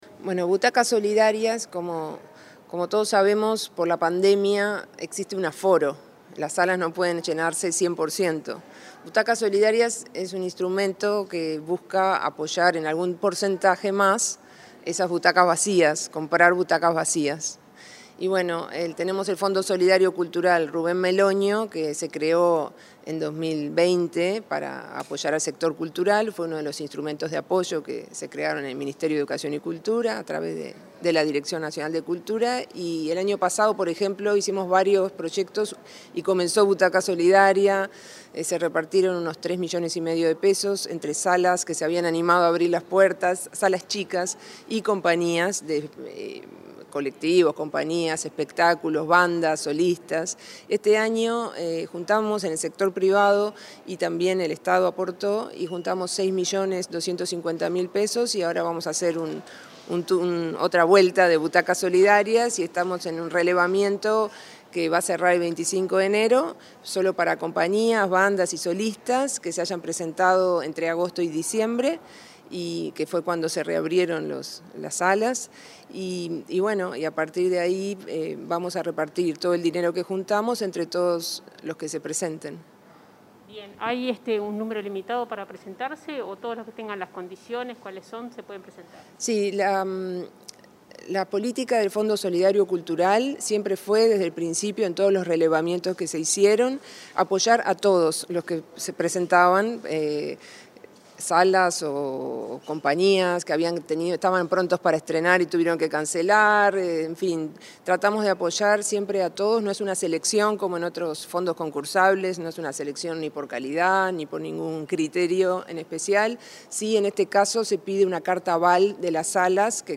Entrevista a la directora nacional de Cultura
La directora nacional de Cultura, Mariana Wainstein, dialogó con Comunicación Presidencial sobre la iniciativa Butaca Solidaria, para apoyar a salas